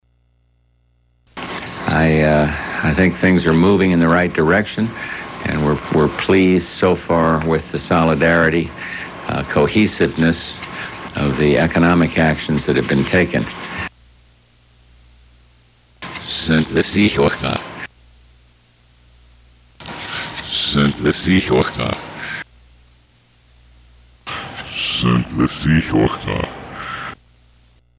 (mp3)という リバース・スピーチも現れていた。